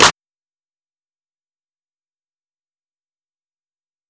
Clap (So Ambitious).wav